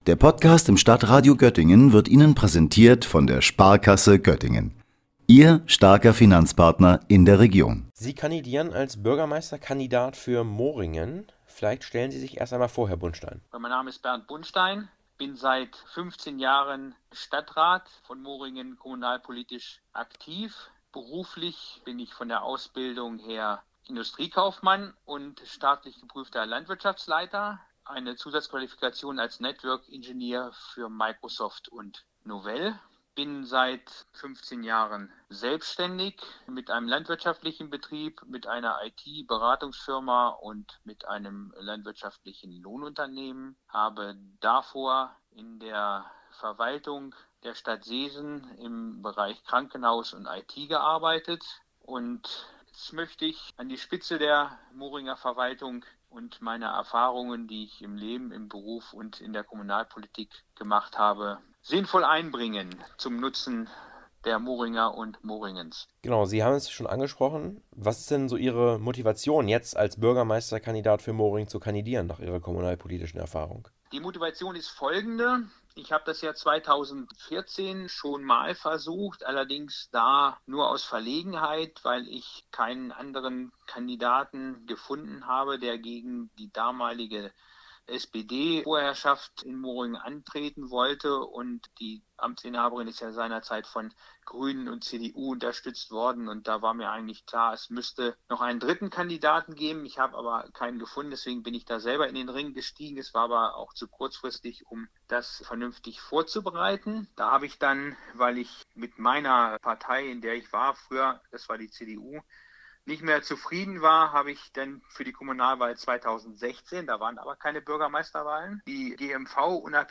Bernd Bundstein ist Fraktionsvorsitzender der GMV im Stadtrat von Moringen.